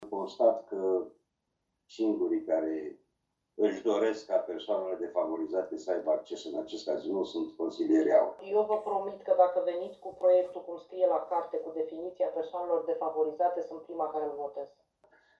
Liderul grupului AUR, consilierul local Ovidiu Cupșa și consilierul independent Felicia Ovanesian: